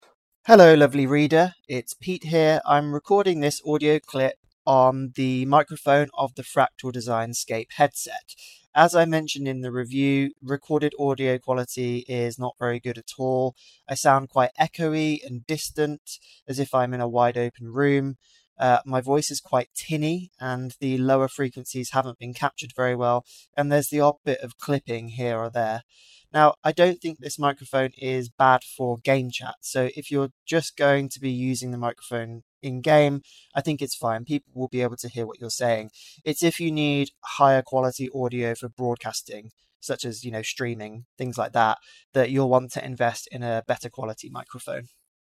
Tinny microphone
Unfortunately, the Scape’s microphone is very poor.
“Tinny,” “quiet,” “distorted,” “Yuck.”
You can hear from the audio clip below that it isn’t a strong show.
Fractal Design Scape Mic Test.mp3